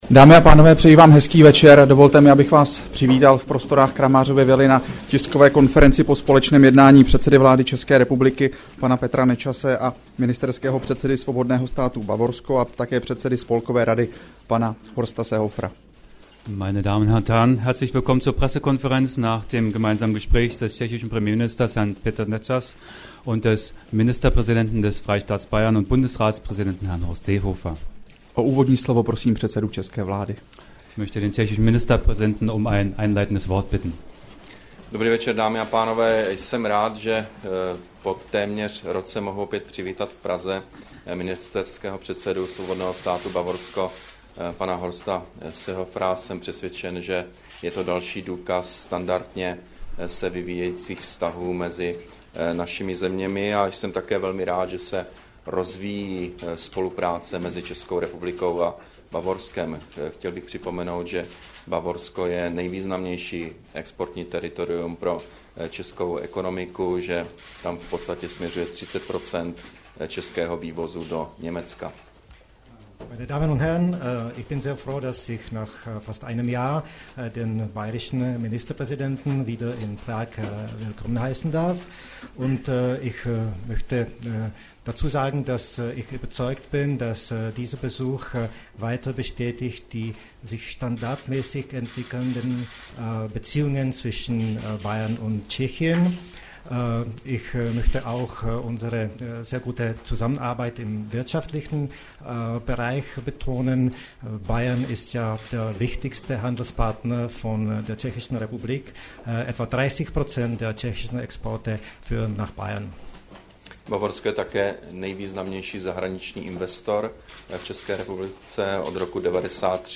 Tisková konference po jednání premiéra Petra Nečase s bavorským ministerským předsedou Horstem Seehoferem, 23. listopadu 2011